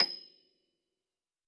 53r-pno27-D6.aif